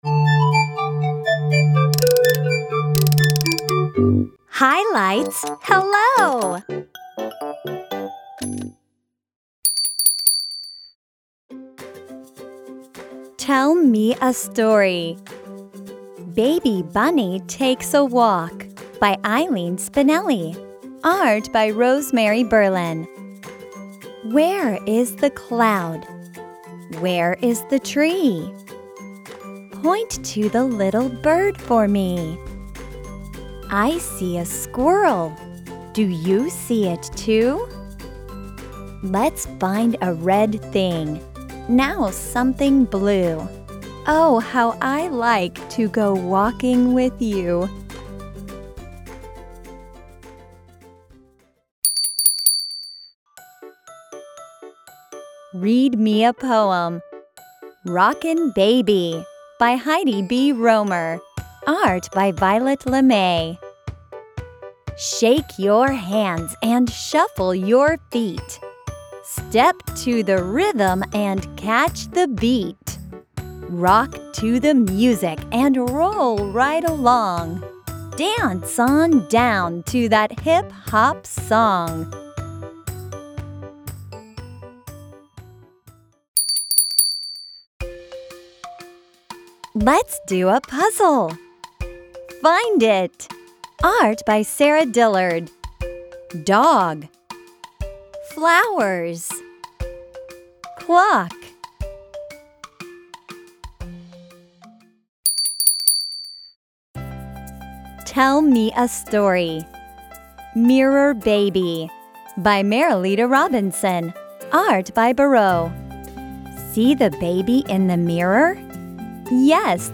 Below you will find audio narration of every book by a native English speaker.